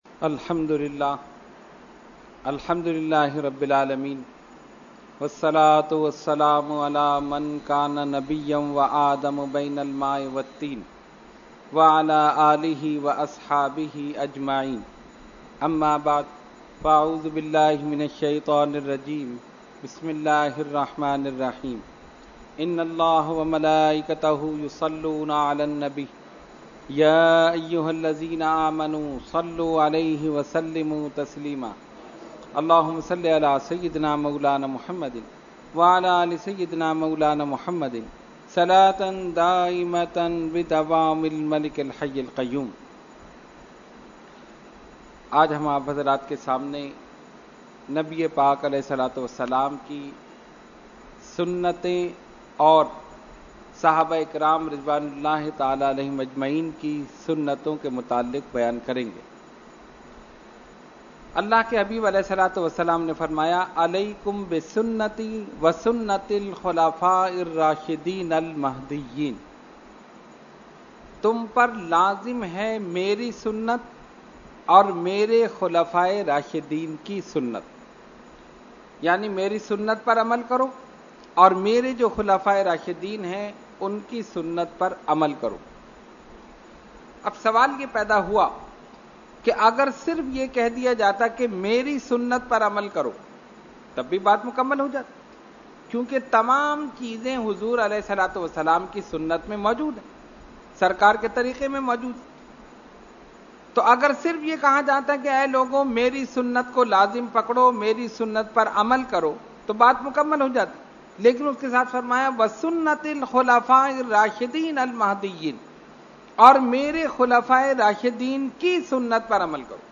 Category : Speech | Language : UrduEvent : Weekly Tarbiyati Nashist